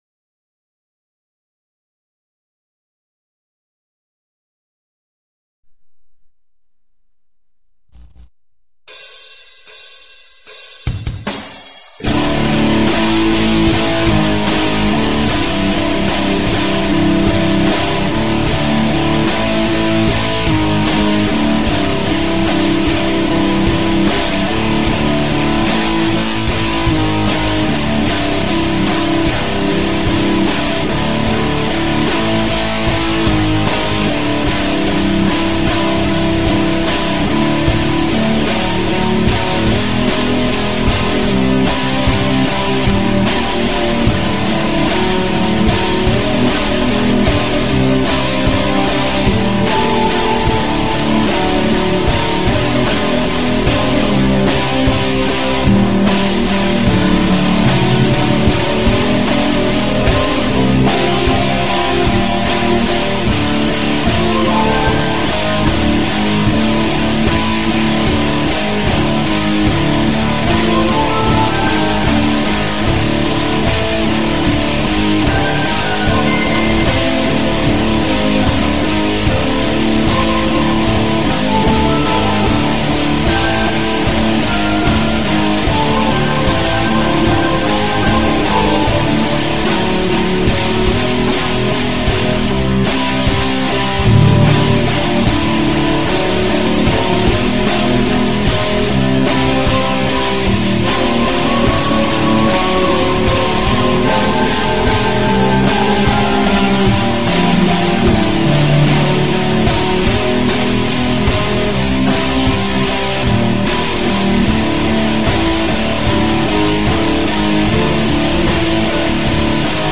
Talk Show Episode, Audio Podcast, The_Crimson_Pill and Courtesy of BBS Radio on , show guests , about , categorized as